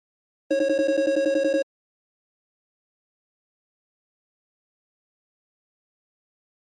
Flash Alarm Electronic Pulse Alarm